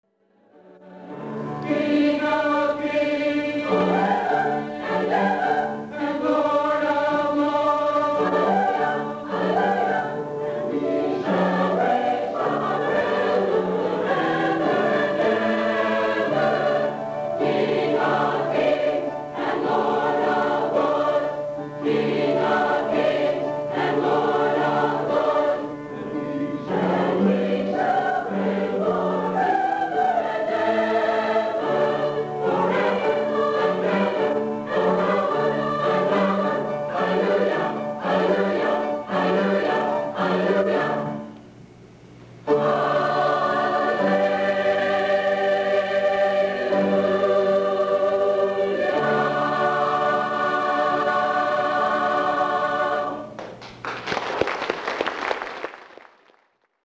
Delta Community Choir
Hallelujah Chorus Finale sung by the Delta Community Choir 2000 The Delta Community Choir would like to thank Wild Alaska for donating our web site space.